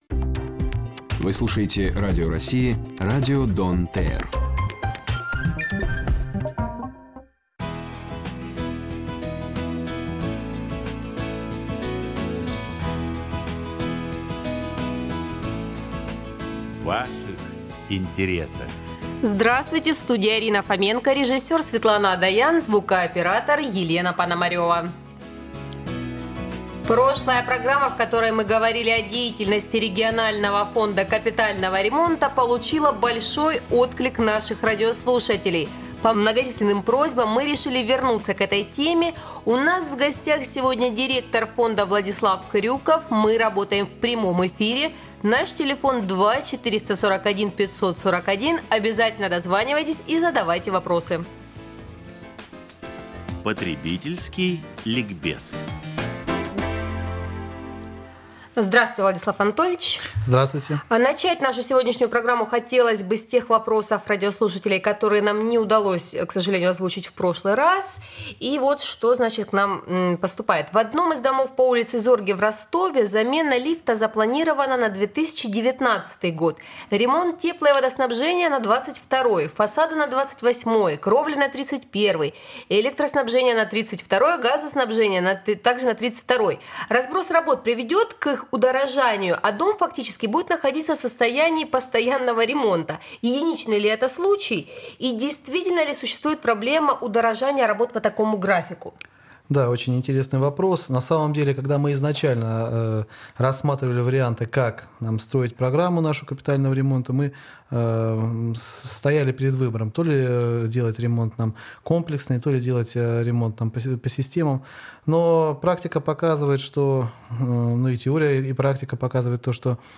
13 августа 2015 года в 13 часов 20 минут , на радио России «Дон-ТР» в прямом эфире вновь вышла программа «В ваших интересах»